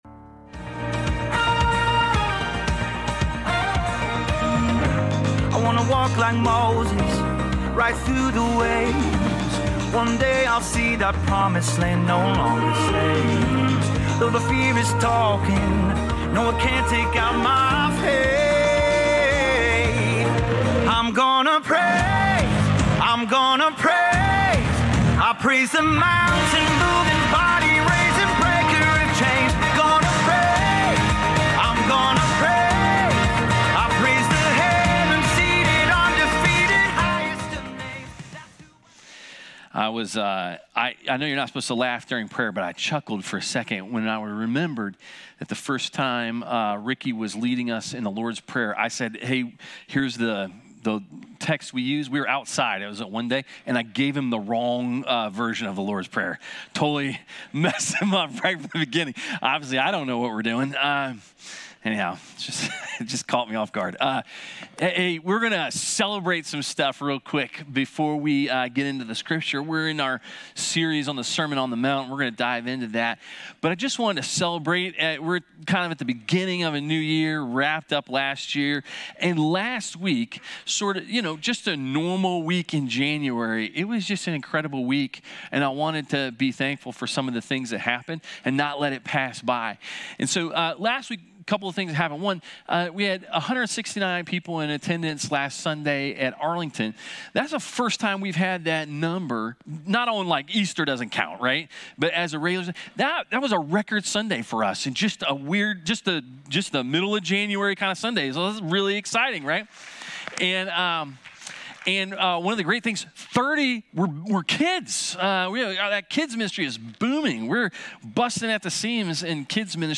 Reconciliation.mp3